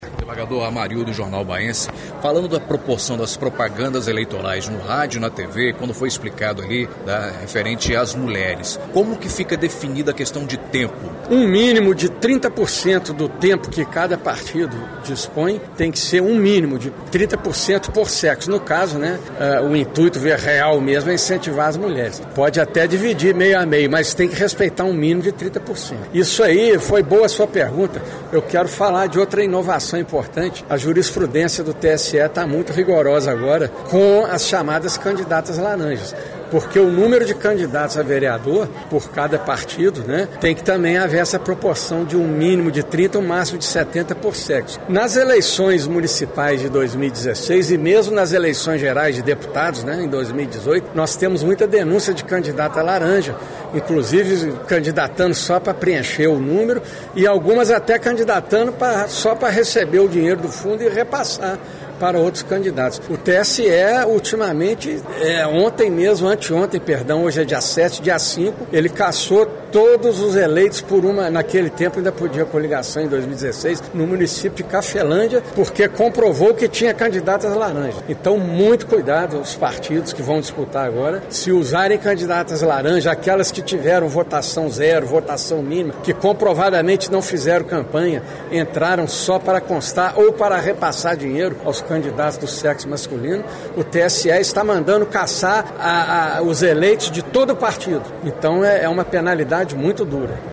As falhas do microfone sem fio durante as apresentações, não chegou a atrapalhar, mas incomodou as mais de 200 pessoas no plenário da Câmara, como também o “ronco berrante” de um dos ar – condicionados.